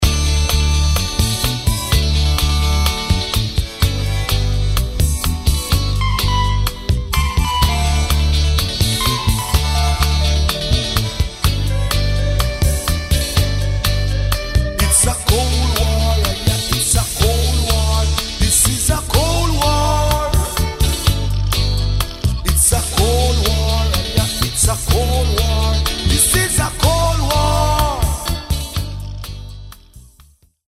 With an Eclectic Roots Rock Reggae!